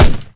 Weapons
Weapon1 (16 kb, .wav)